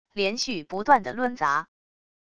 连续不断的抡砸wav音频